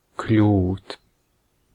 Ääntäminen
US : IPA : /ˈgɛt/